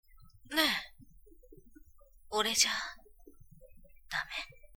セリフ
猫っ被りでお色気担当だそうで…情景的には肩を抱かれてささやく感じで演じてみたのですが、その情景が思い浮かべばいいとお思います！